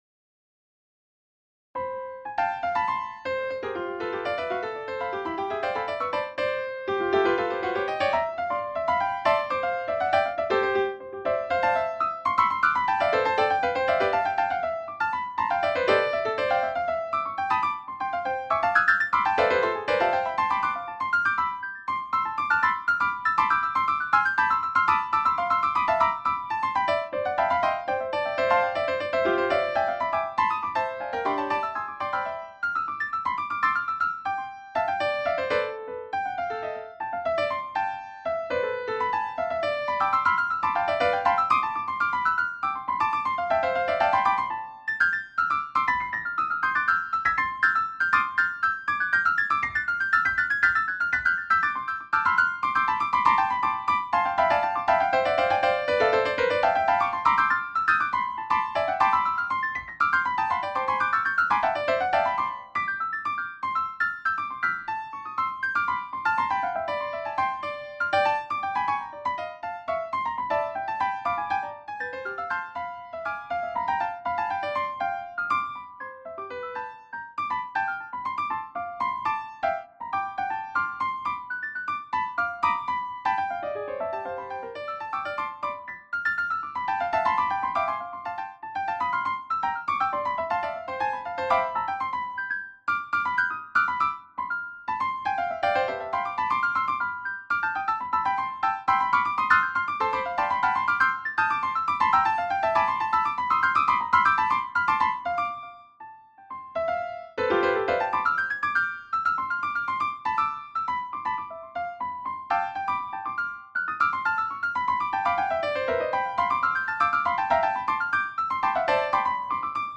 カテゴリー: 練習